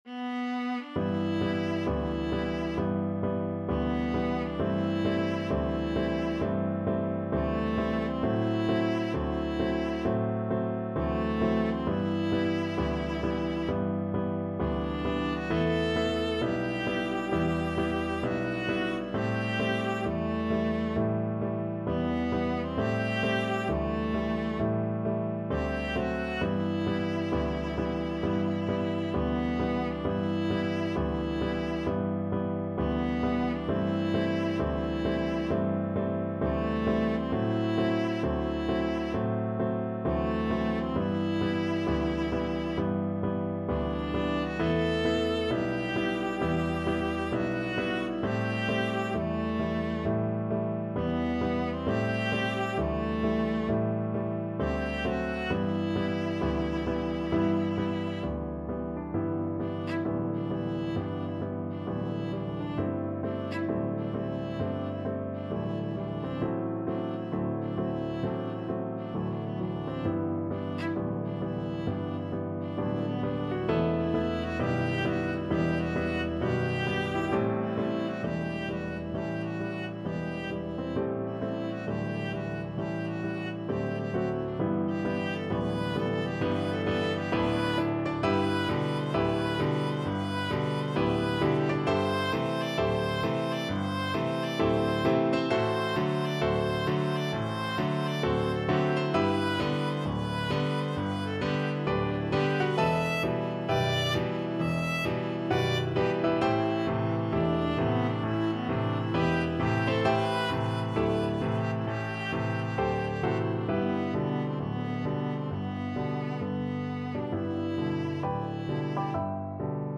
Viola version
2/2 (View more 2/2 Music)
E4-E6
Blues Tempo (=66)
Jazz (View more Jazz Viola Music)